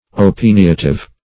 Search Result for " opiniative" : The Collaborative International Dictionary of English v.0.48: Opiniative \O*pin"ia*tive\, a. Opinionative.